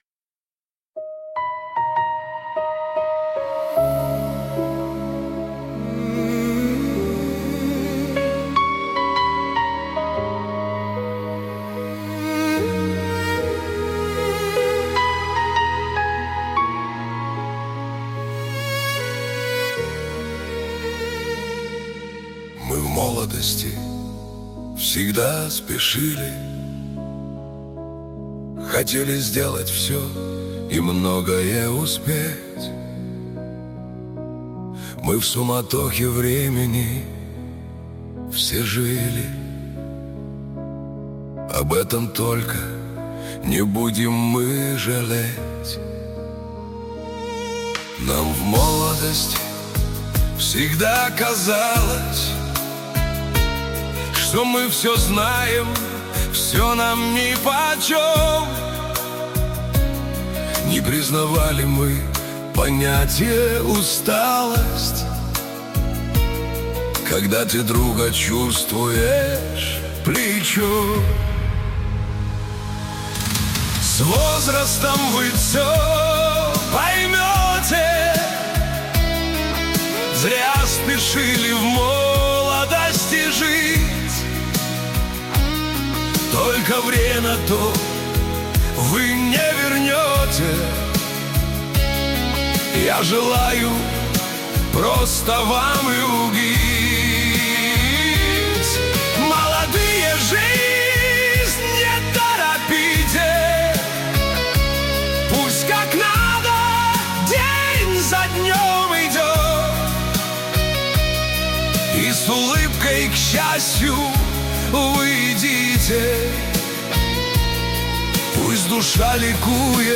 Очень трогательно